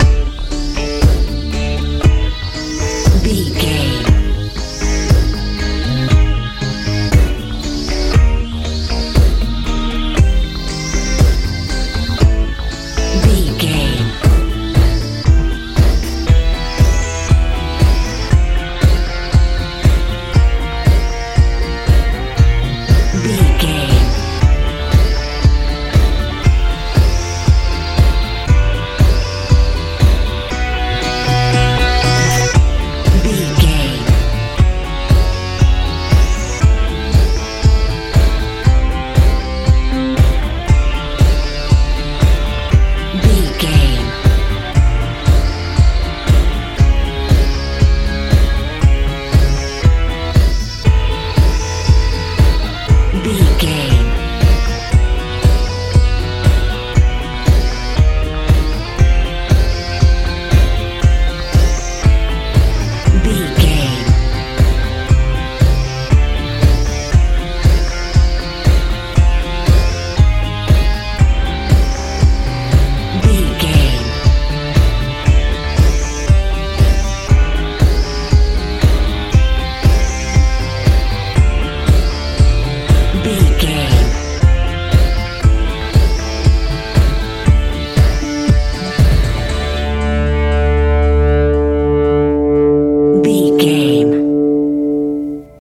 dance feel
Ionian/Major
groovy
powerful
synthesiser
electric guitar
bass guitar
drums
80s
90s